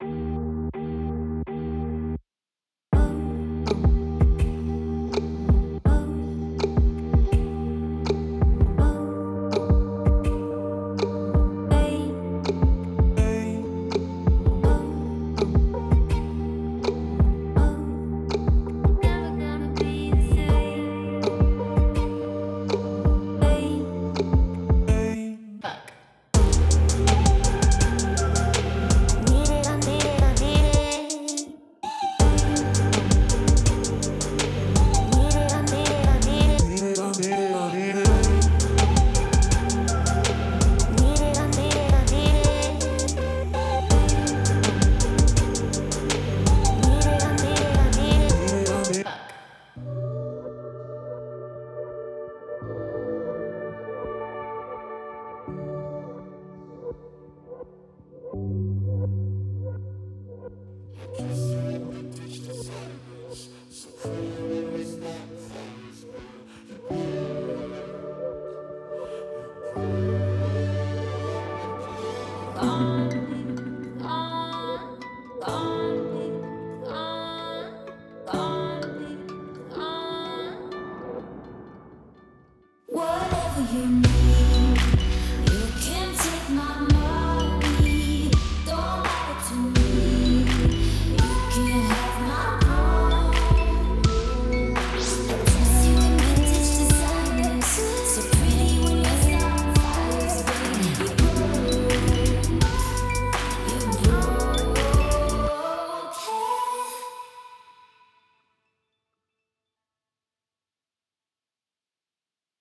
141 LOOPS
37 ONE SHOTS